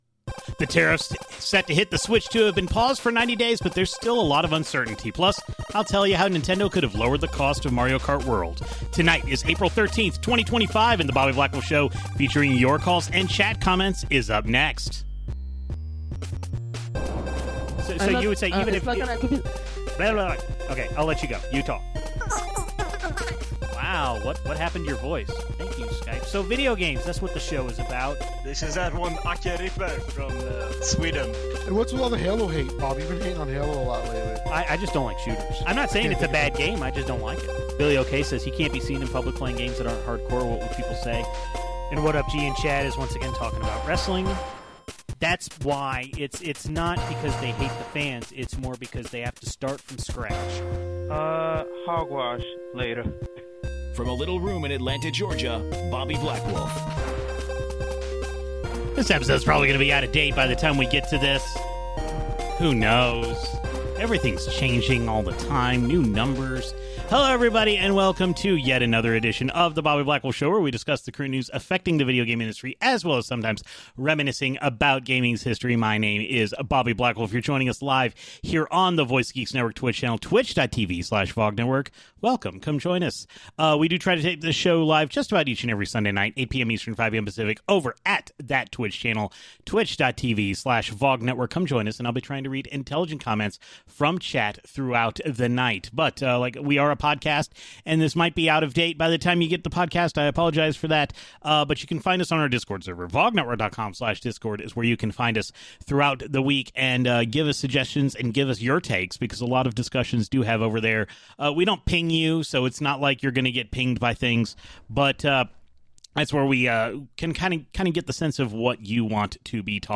A weekly internet radio show and podcast discussing the latest news in gaming as well as sometimes reminiscing about gaming's history. It records LIVE on Sundays at 8PM Eastern/5PM Pacific on the VOG (Voice of Geeks) Network Twitch Channel while taking live calls via Discord and including chat room interaction.